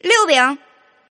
Index of /client/common_mahjong_tianjin/mahjonghntj/update/1308/res/sfx/tianjin/woman/